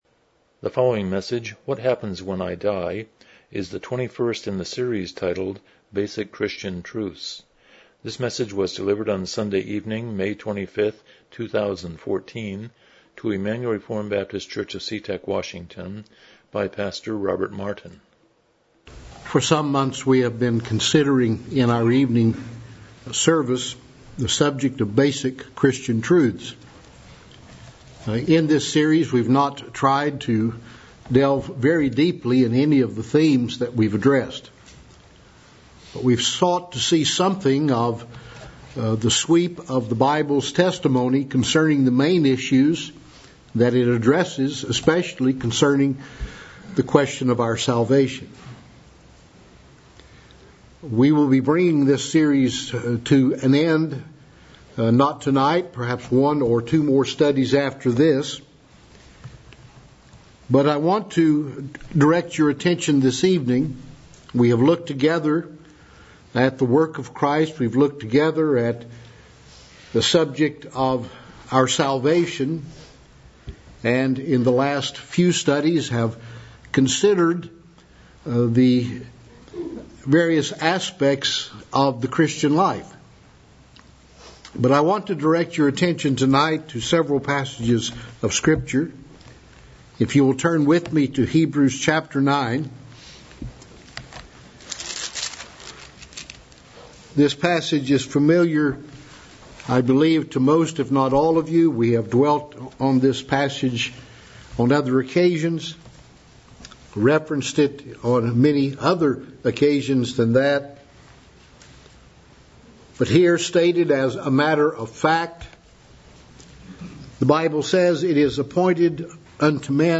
Basic Christian Truths Service Type: Evening Worship « 55 Need of Christ